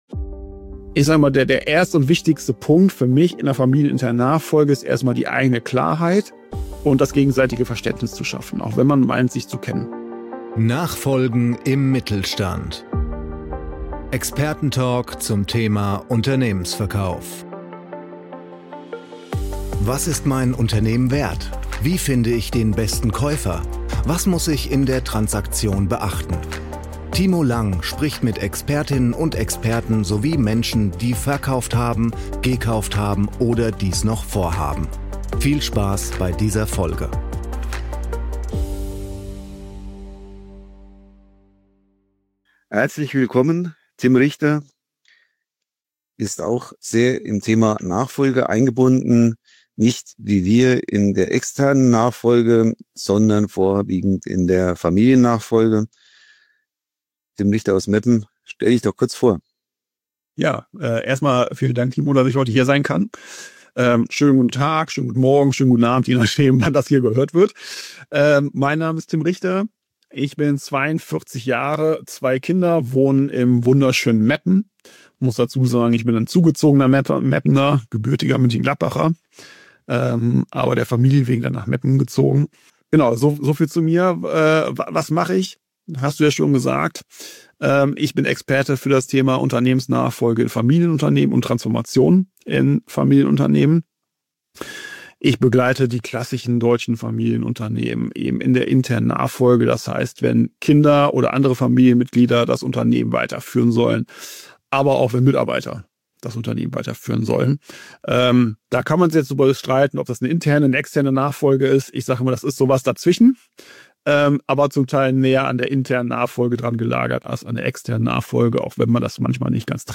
Experten-Talk zum Thema Unternehmensverkauf